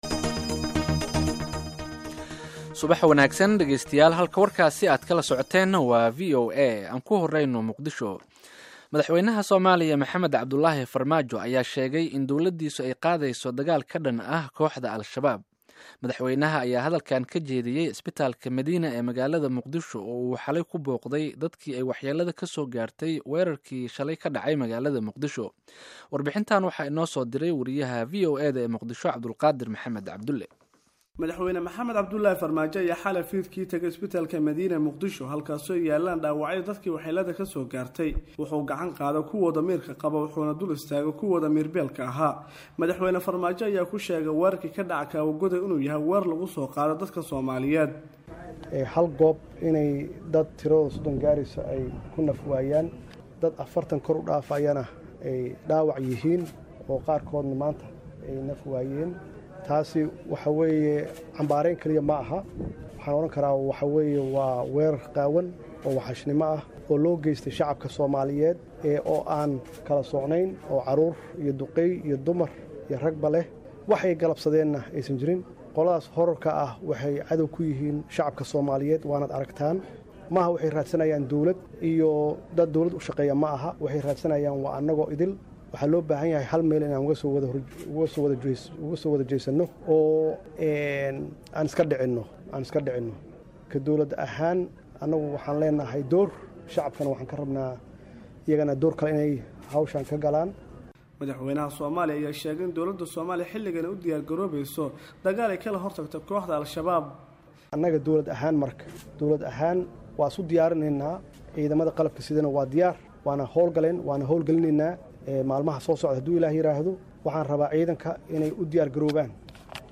Dhageyso warbixinta Madaxweyne Farmaajo